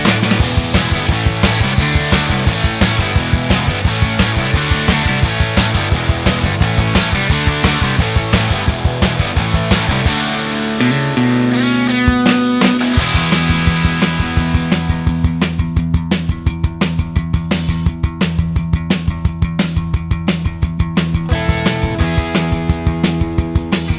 Minus Guitars Pop (1980s) 3:59 Buy £1.50